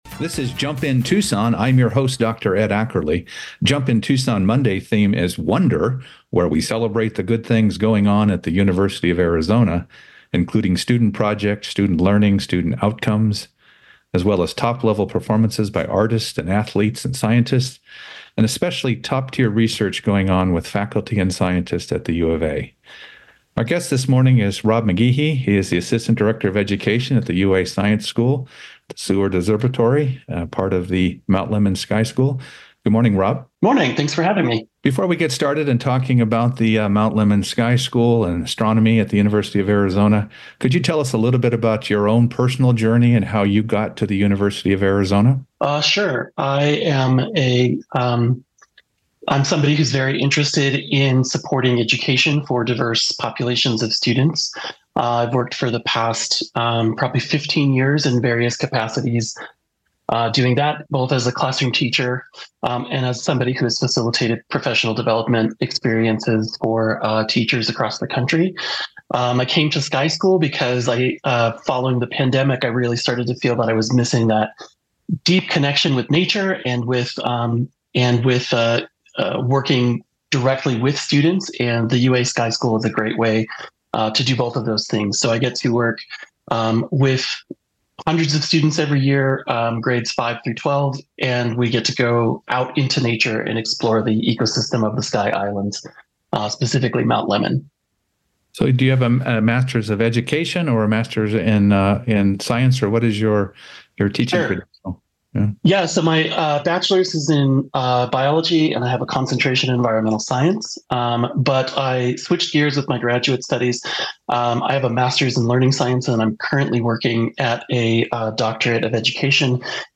Based on the 8/12/24 Jump In Tucson on KVOI-1030AM in Tucson, AZ.